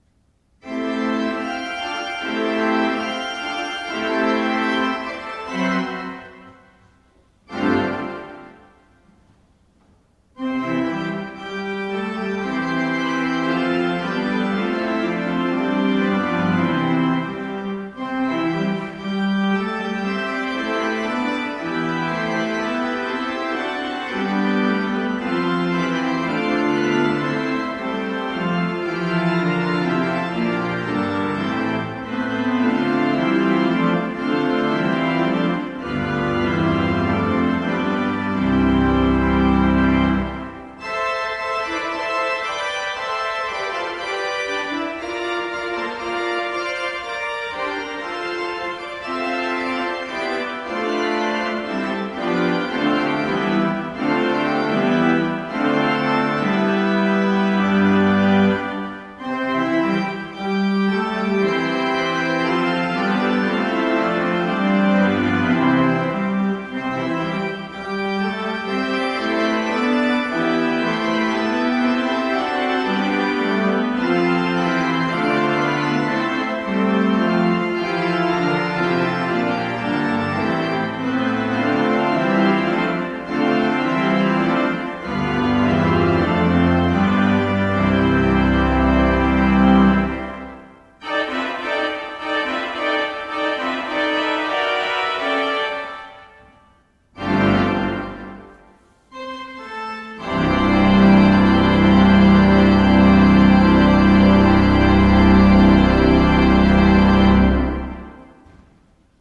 All other tracks are at St Anns Manchester.